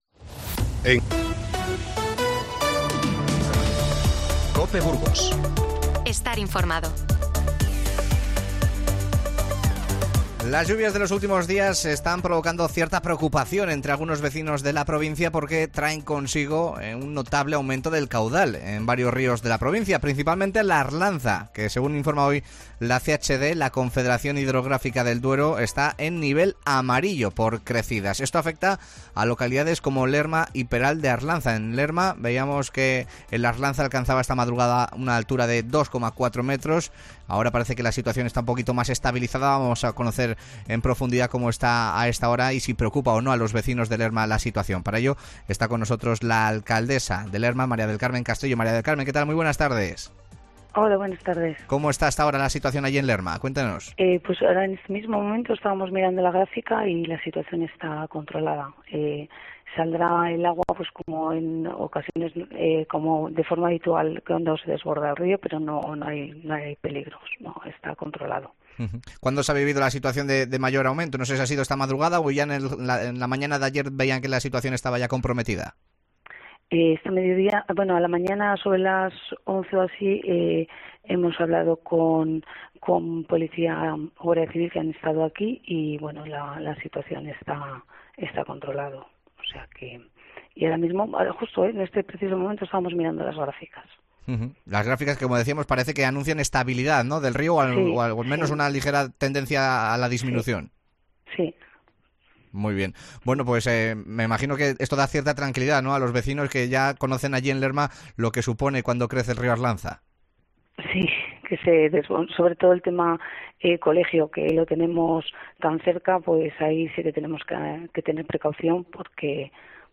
14:33 1 min lectura Descargar Facebook Twitter Whatsapp Telegram Enviar por email Copiar enlace El río Arlanza está en nivel amarillo por crecidas en Lerma y Peral de Arlanza. Hablamos con sus alcaldes para conocer la última hora.